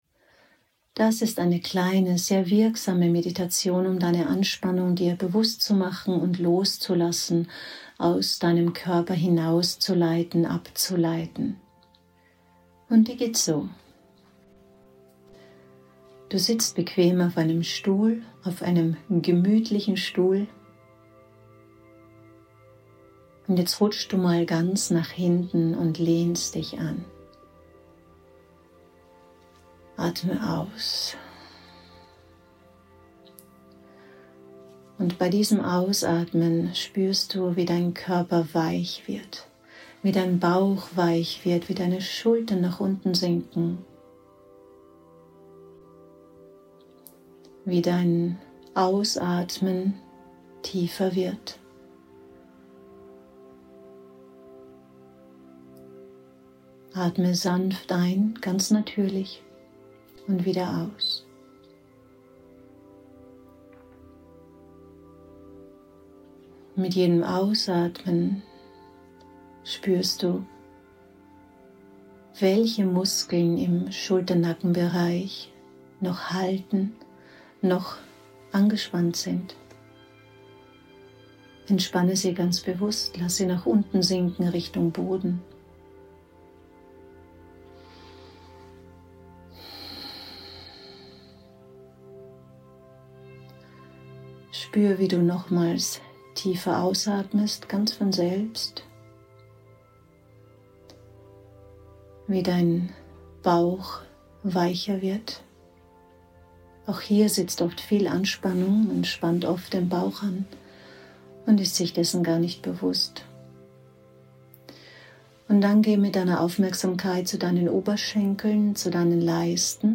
Meditation - innere Anspannung bewusst ausleiten